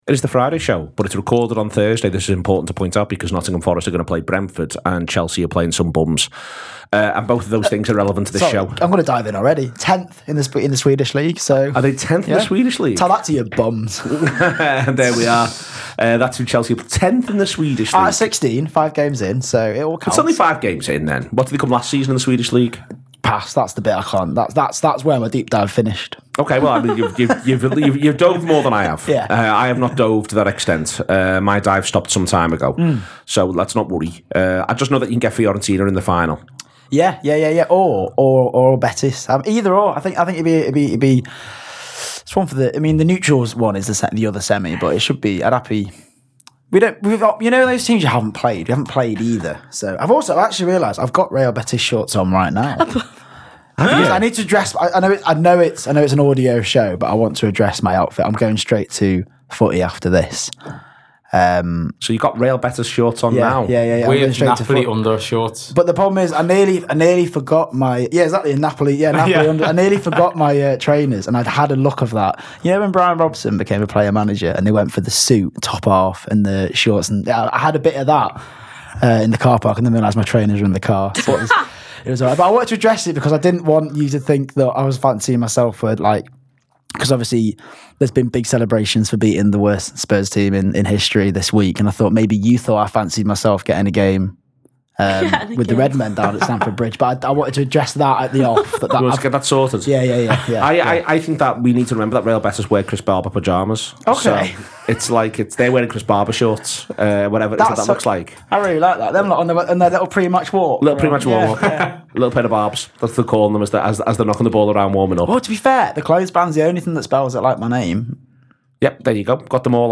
The Anfield Wrap’s preview show looking towards the weekends fixtures.